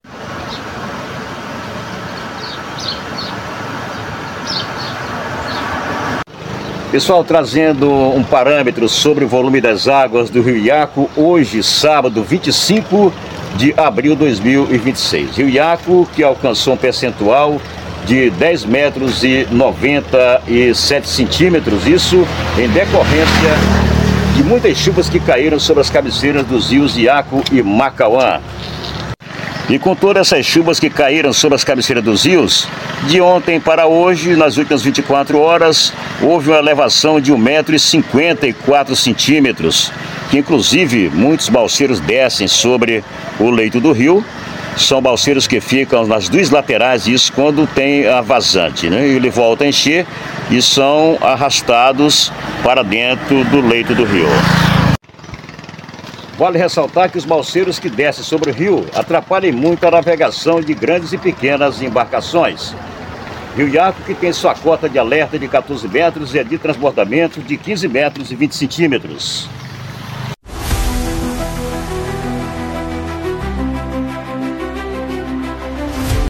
esteve na ponte Ponte José Nogueira Sobrinho, em Sena Madureira, para atualizar a população sobre o nível do Rio Iaco, que voltou a subir nas últimas horas.